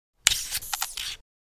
Download Free Military Equipment Sound Effects | Gfx Sounds
Night-vision-goggles-removing-2.mp3